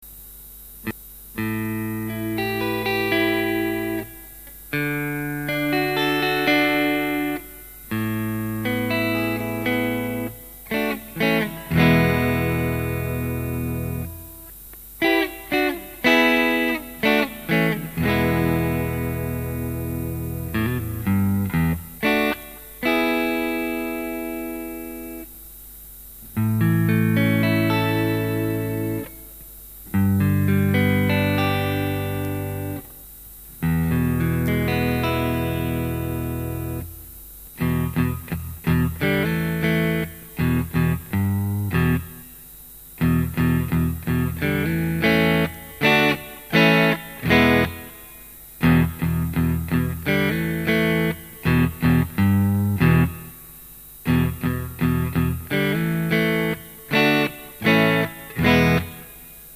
Humbucker levels of output and predominantly humbucker tone, with just enough bite for clarity, plus traditional Strat focus and attack. Warm but clear clean tones and rock solid overdrives.
Listen here:     Bridge    Bridge & Middle